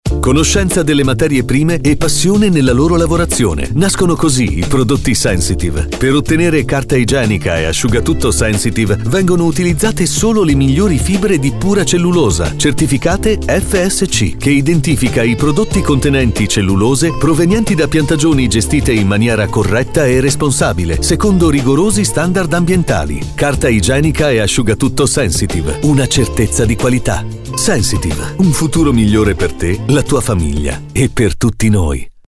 Roto-Cart s.p.a lancia lo spot radio per promuovere i prodotti della linea Sensitive certificati FSC